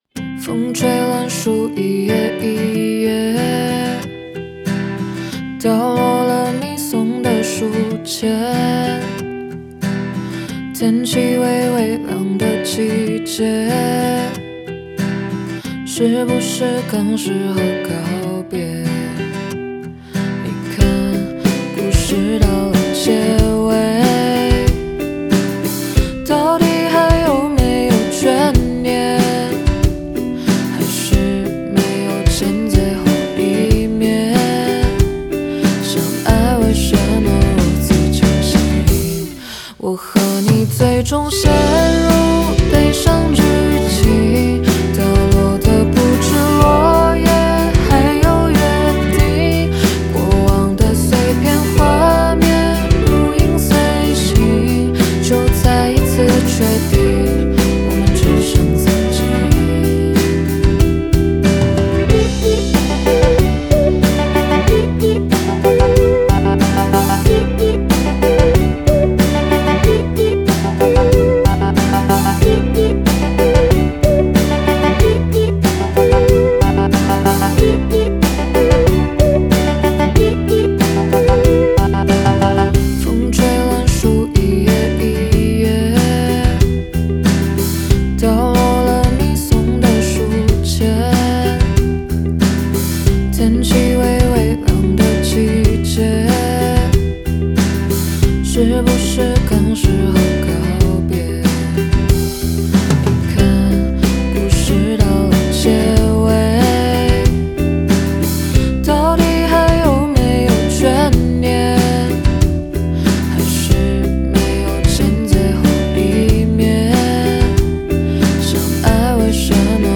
Ps：在线试听为压缩音质节选，体验无损音质请下载完整版
弦乐Strings
吉他Guitar